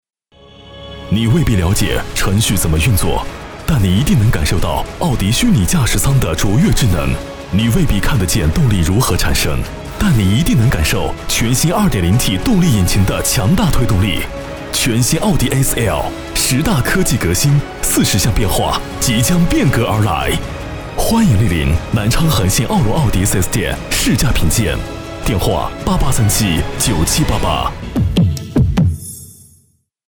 • 男S317 国语 男声 宣传片-奥迪全新A4L-汽车宣传片-沉稳大气 沉稳|娓娓道来|积极向上